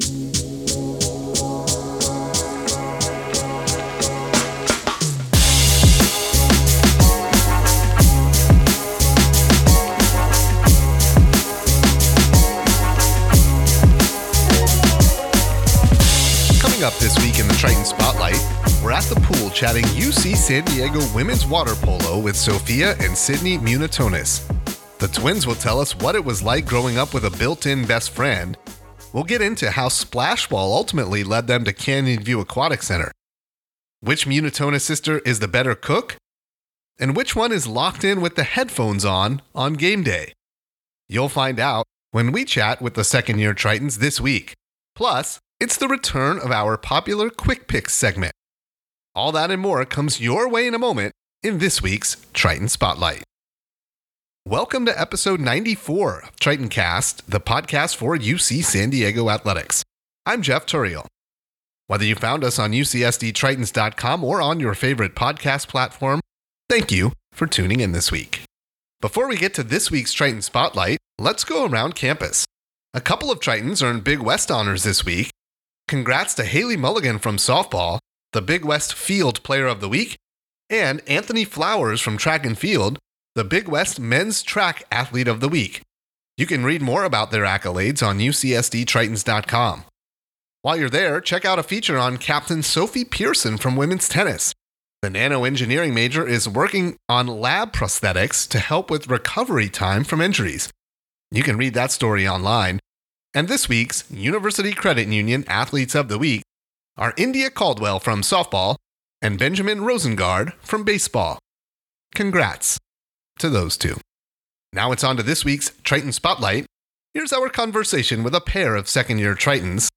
March 06, 2024 Tritoncast is back with an all-new episode this week. As part of women's history month, we're at the pool chatting UC San Diego women's water polo